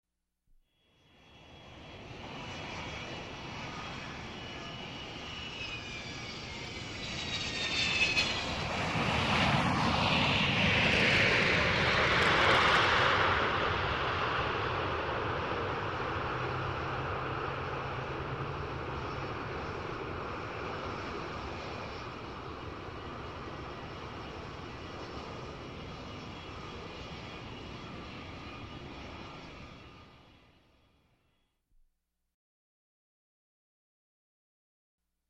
Звуки истребителя
Стереозвук: реактивный истребитель проносится на сверхзвуковой скорости